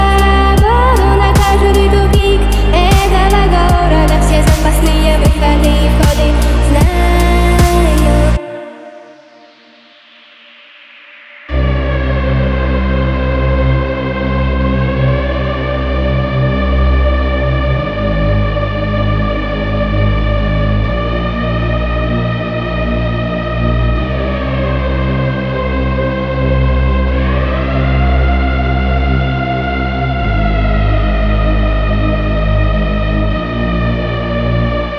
Жанр: Электроника / Русские